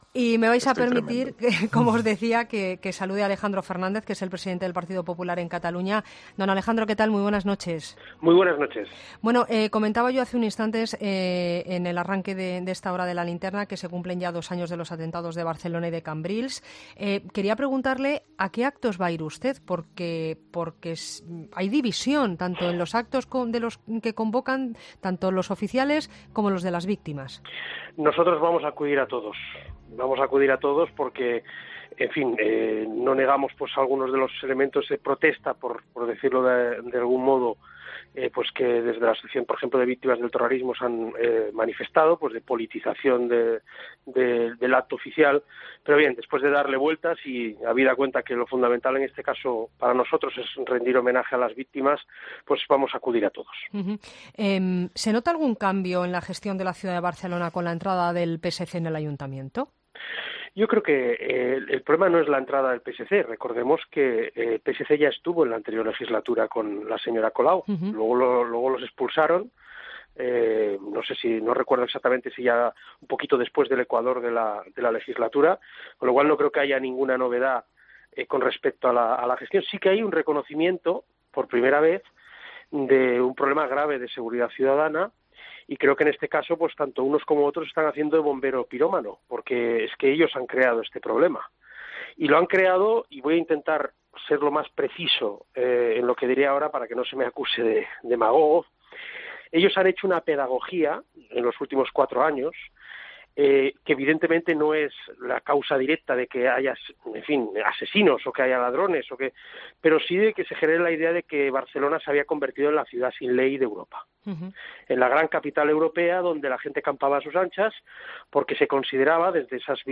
El presidente del Partido Popular en Cataluña analiza la actualidad catalana y nacional en 'La Linterna' en COPE